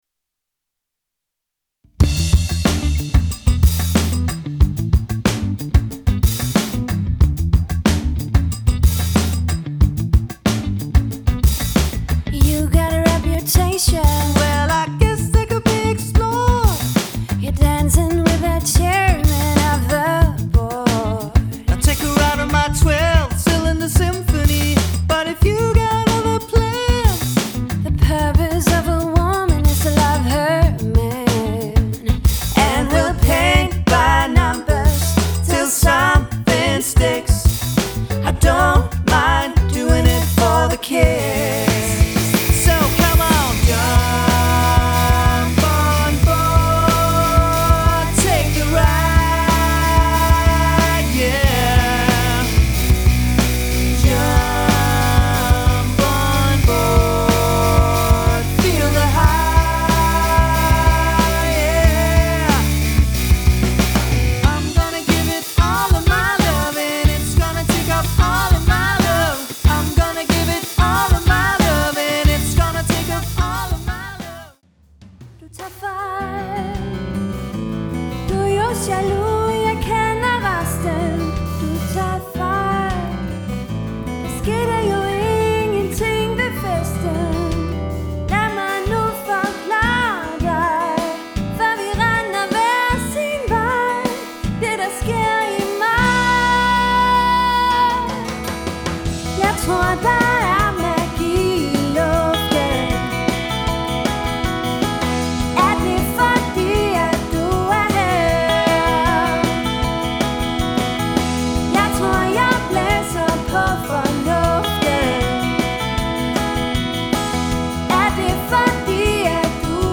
• Allround Partyband
• Coverband
• Rockband